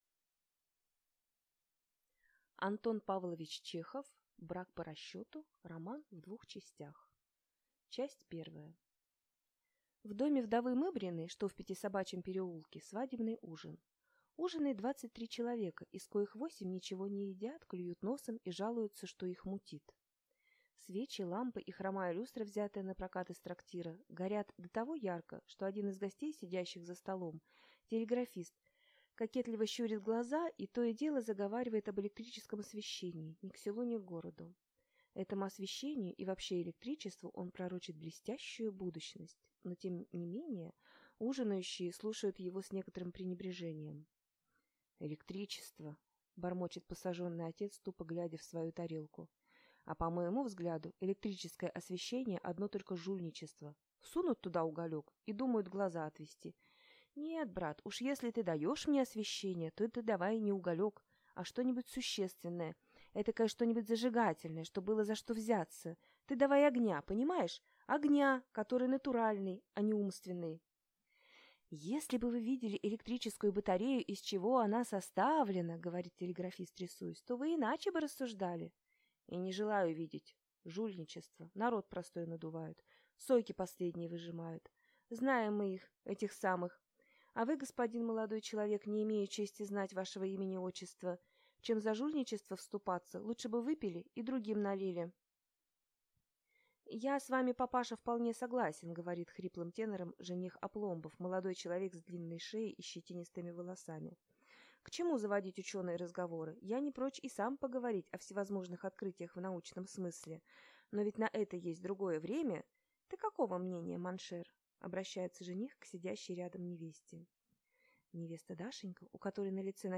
Аудиокнига Брак по расчету | Библиотека аудиокниг